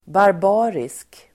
Uttal: [barb'a:risk]